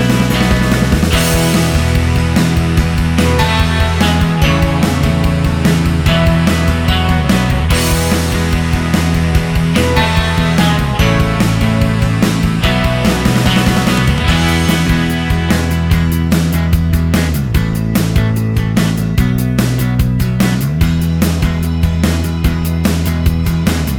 Minus Solo Guitars Rock 4:27 Buy £1.50